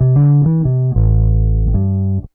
BASS 2.wav